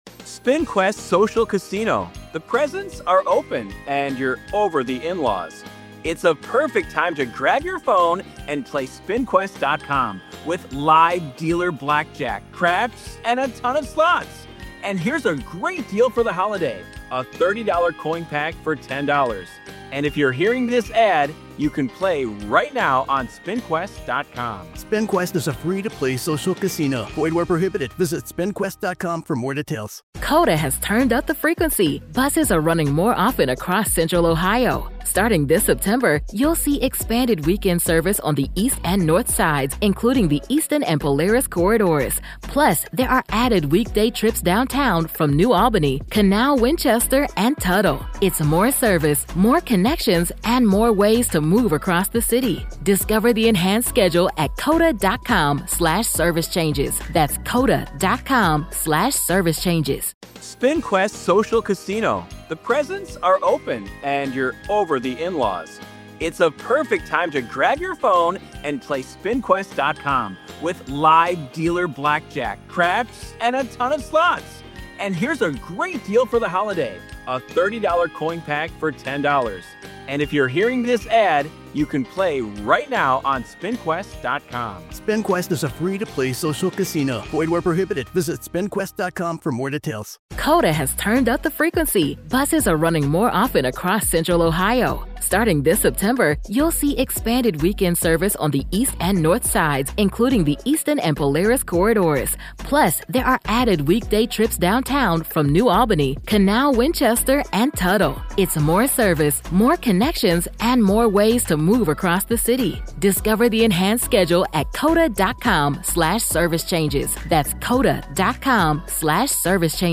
If you’ve ever wondered what happens when history and haunting collide, this is the interview that opens the door.